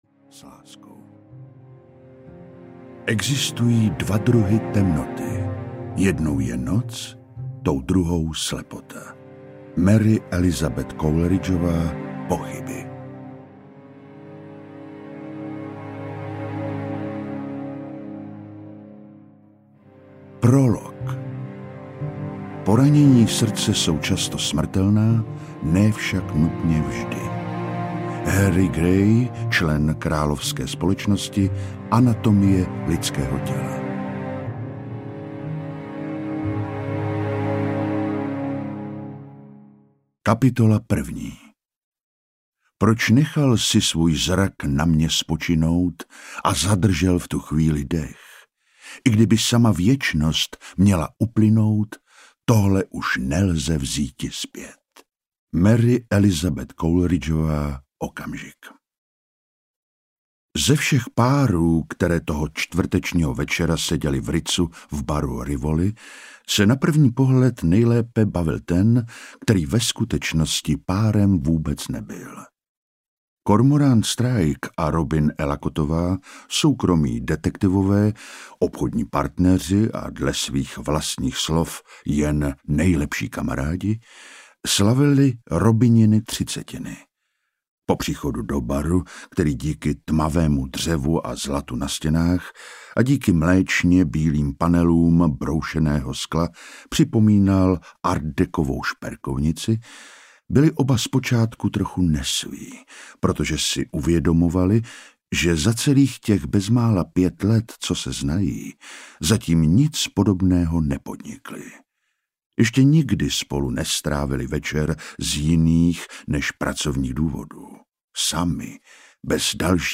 Černočerné srdce audiokniha
Ukázka z knihy
cernocerne-srdce-audiokniha